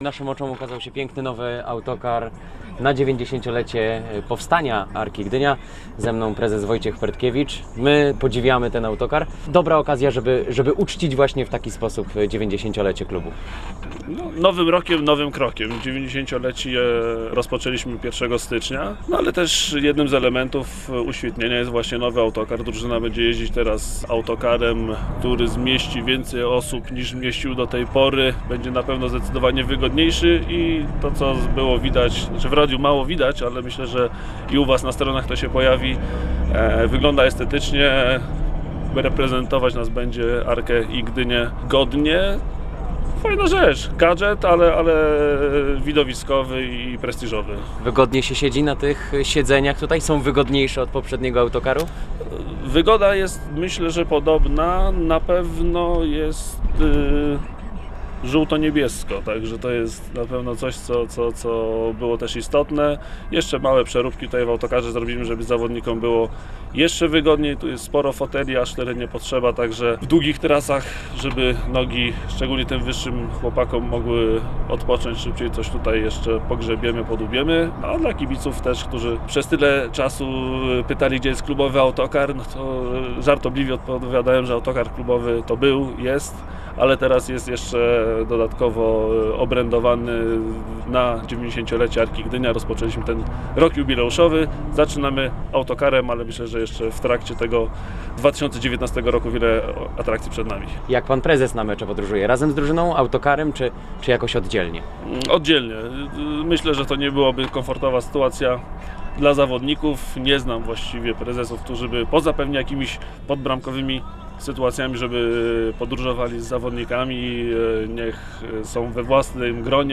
ale kusi piłkarzy „ósemką” [ROZMOWA]